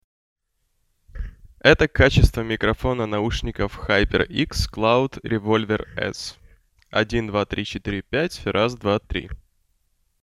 3.3 HyperX Cloud Revolver S тест микрофона
Микрофон HyperX Cloud Revolver S показался мне хорошим. Речь считывает чисто.
Микрофон с функцией шумоподавления и сертификацией TeamSpeak™ и Discord.